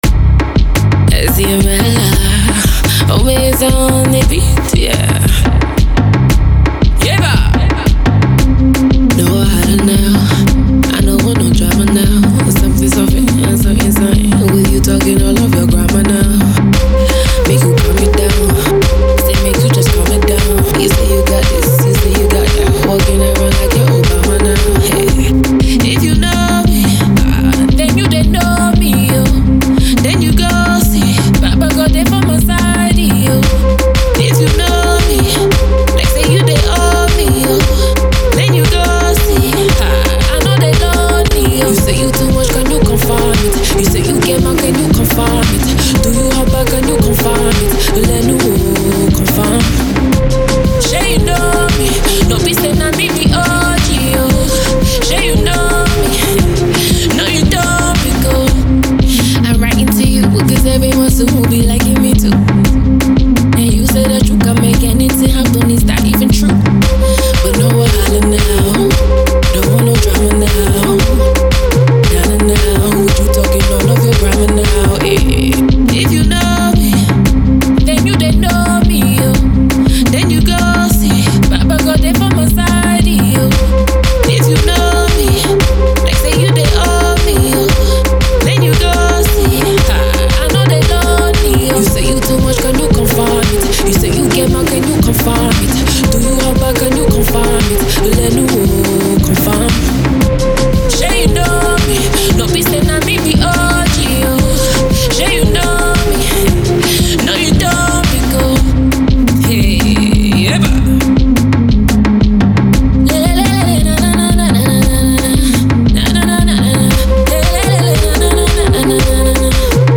Rising Nigerian singer